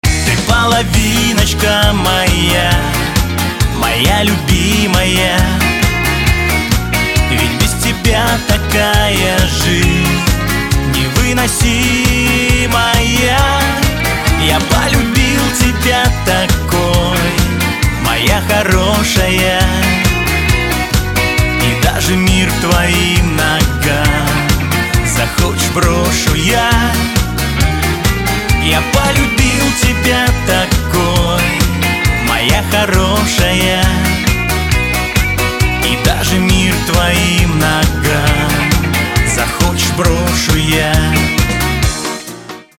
• Качество: 256, Stereo
мужской вокал
русский шансон
шансон